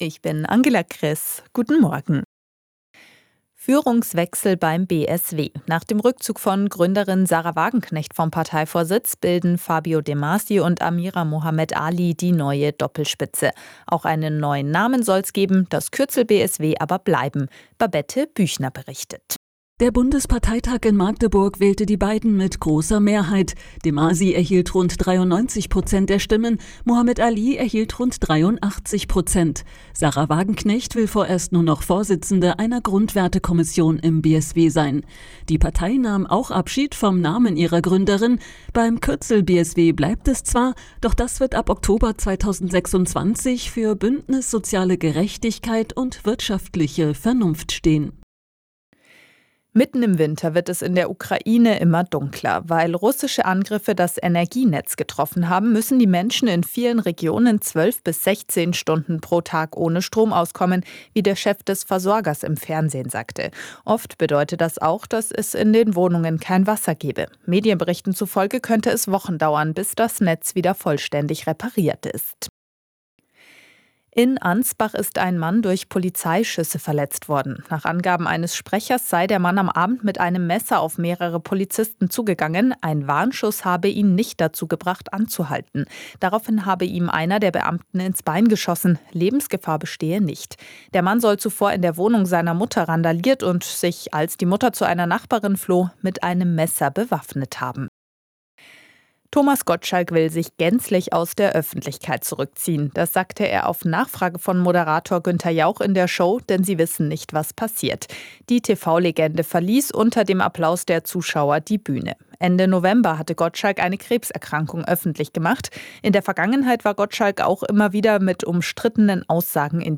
Die Arabella Nachrichten vom Sonntag, 7.12.2025 um 07:59 Uhr